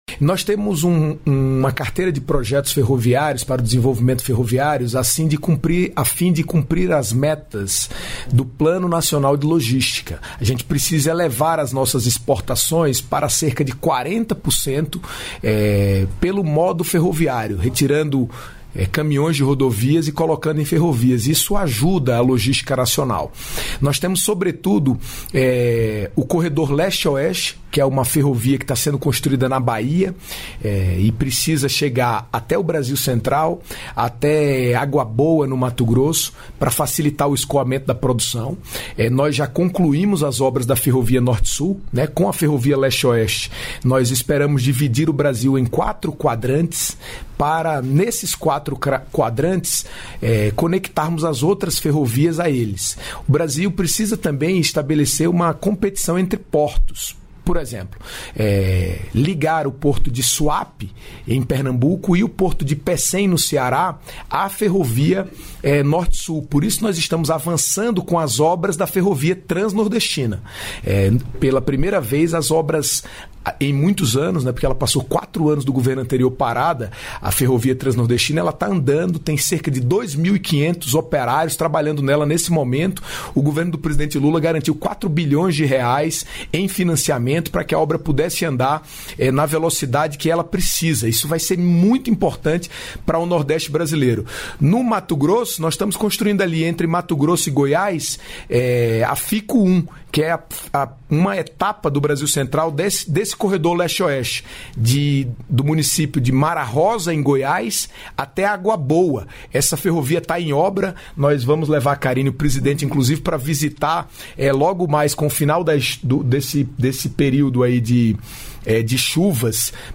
Trecho da participação do ministro dos Transportes, Renan Filho, no programa "Bom Dia, Ministro" desta quinta-feira (10), nos estúdios da EBC em Brasília (DF).